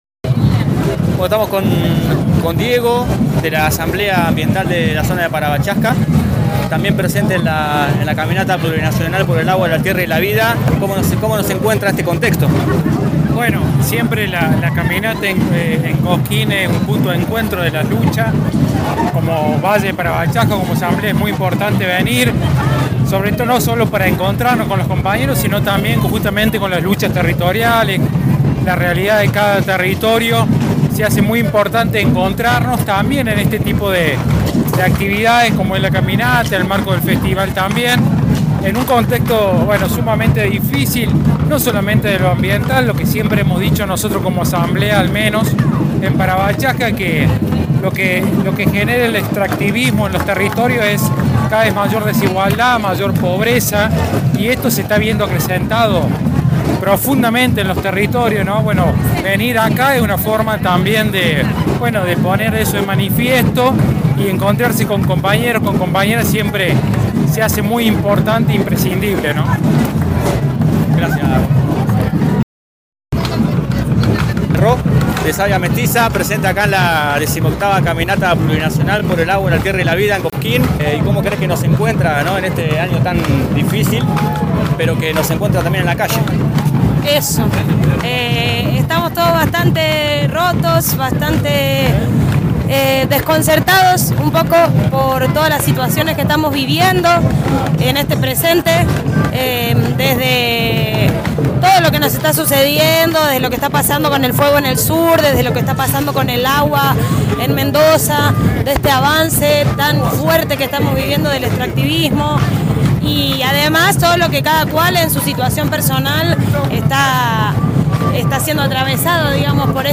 En esta segunda parte de una cobertura que además fue colaborativa y colectiva entre espacios comunicacionales y comunicadores de punilla centro, dejamos primeramente el audio en el cual recopilamos algunos testimonios y sensaciones durante la caminata.